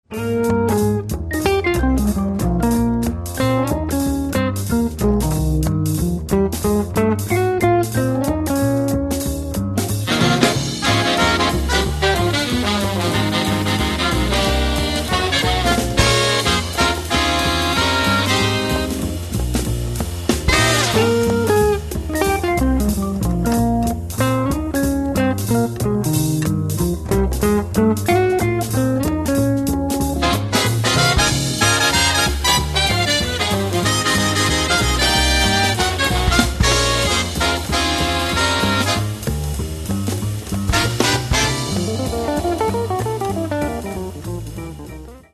сполучає латиноамериканський та європейський колори джазу
гітара
контрабас
труба
альт саксофон
баритон саксофон